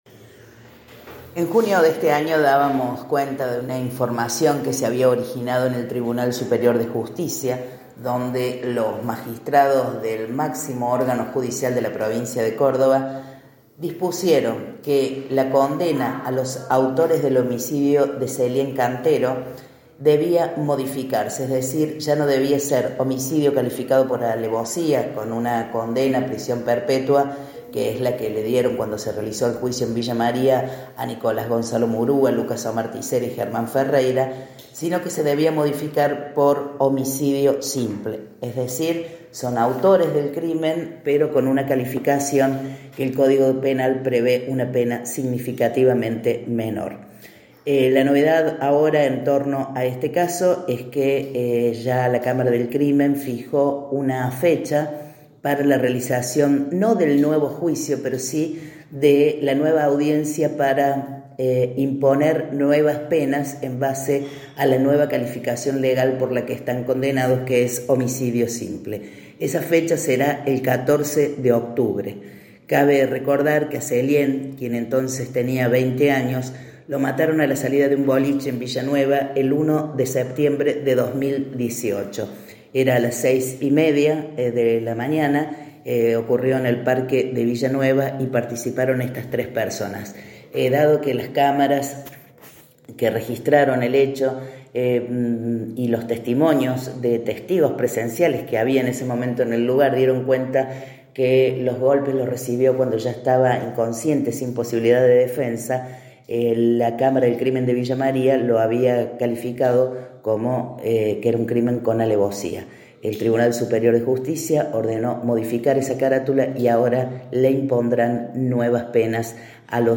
Audio: informe